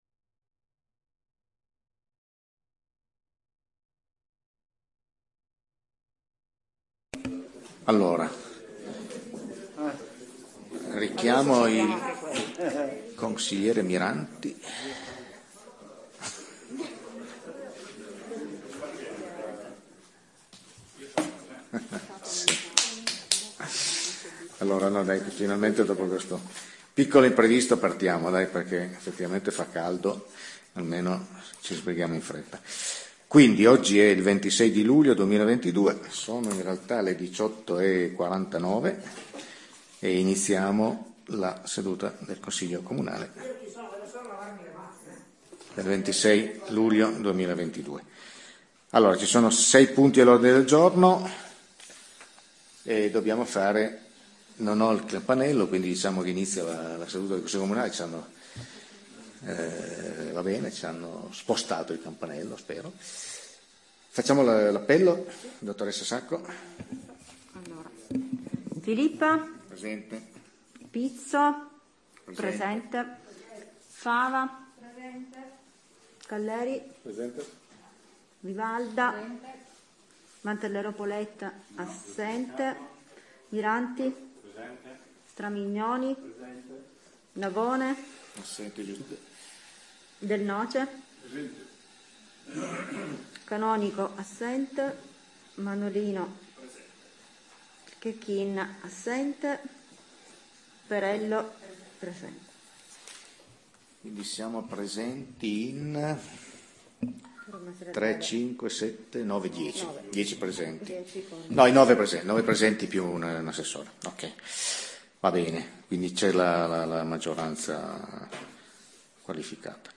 Registrazione Consiglio comunale - Municipality of Pecetto Torinese